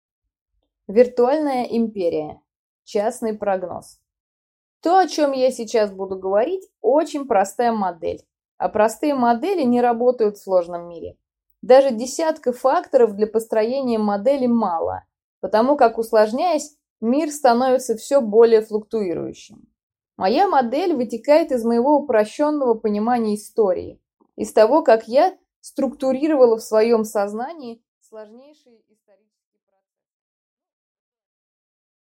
Аудиокнига Виртуальная империя | Библиотека аудиокниг